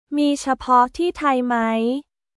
ミー チャポ ティータイ マイ